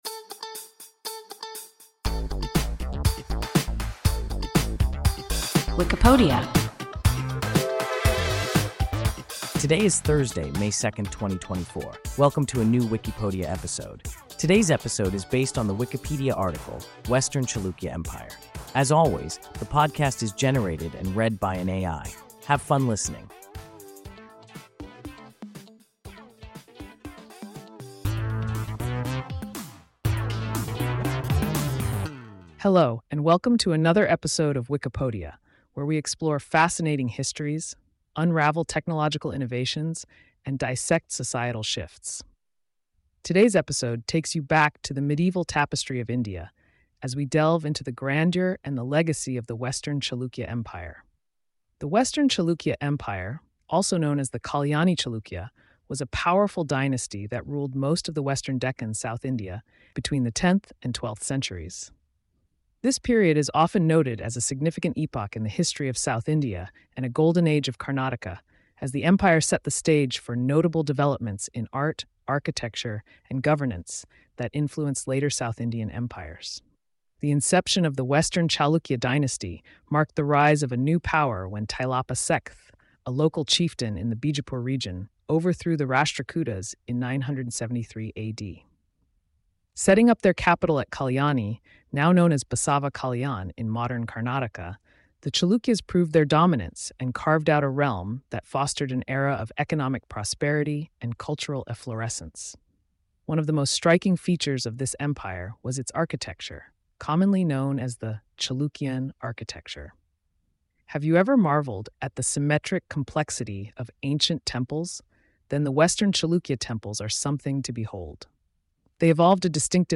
Western Chalukya Empire – WIKIPODIA – ein KI Podcast